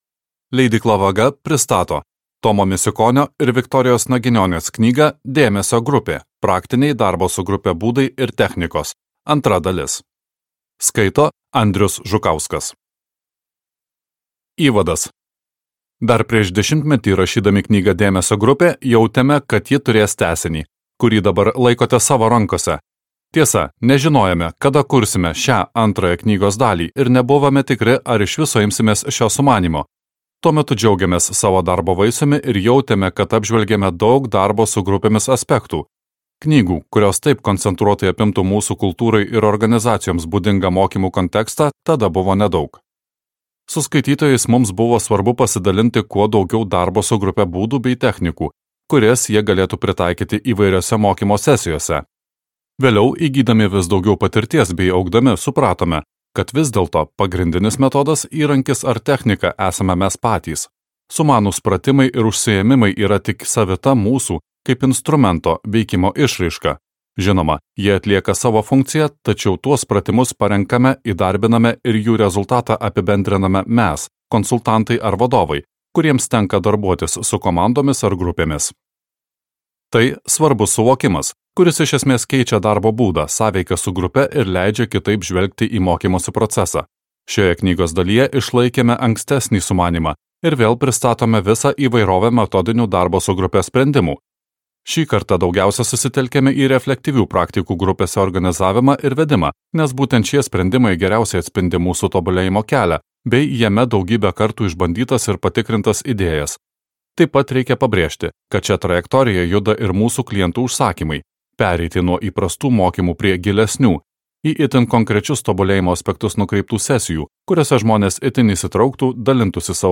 Praktiniai darbo su grupe būdai ir technikos | Audioknygos | baltos lankos